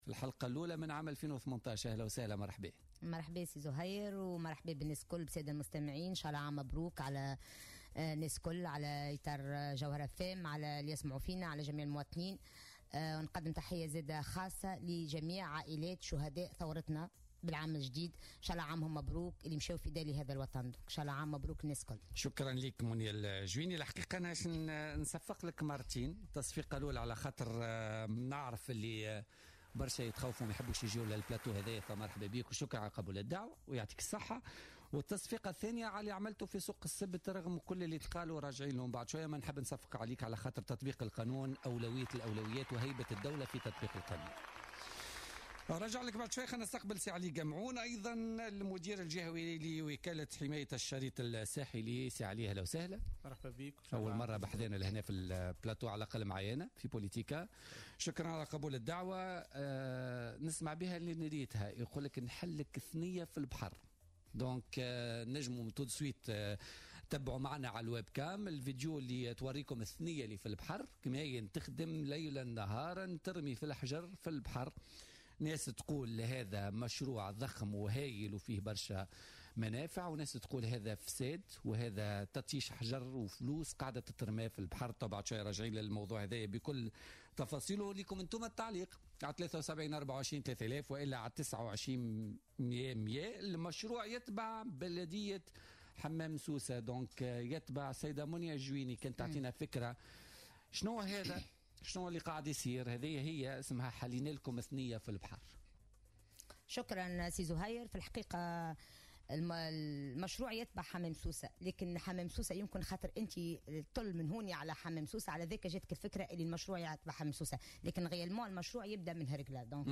قالت معتمدة حمام سوسة ورئيسة النيابة الخصوصية منية الجويني ضيفة بولتيكا اليوم 2 جانفي 2018 إن مشروع مشروع انجاز أشغال حماية الشريط الساحلي من الانجراف هو مشروع يبدأ أساسا من هرقلة ويشمل في جزء بسيط منه حمام سوسة.